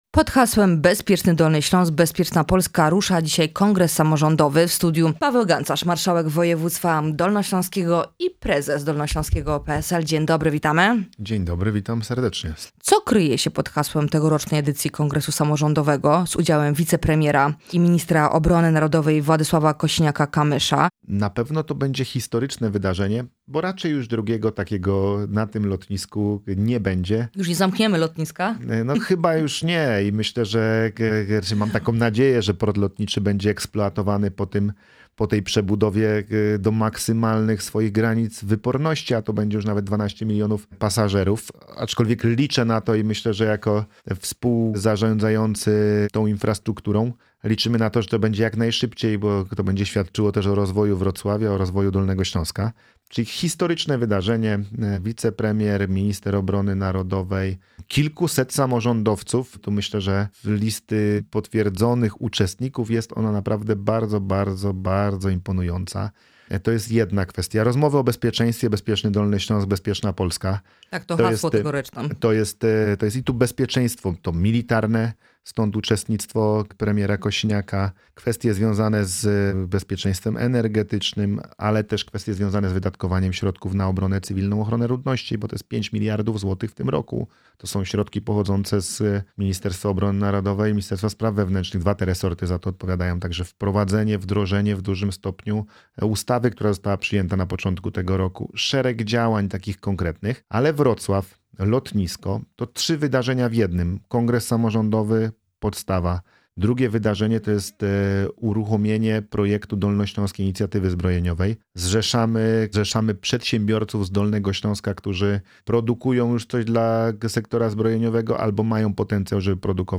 Posłuchaj rozmowy z marszałkiem Pawłem Gancarzem: